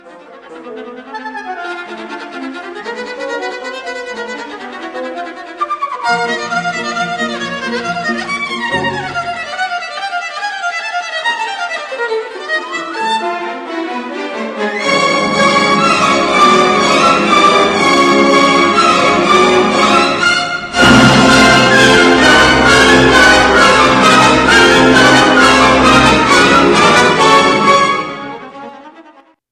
تکنوازی ویولون آلتوی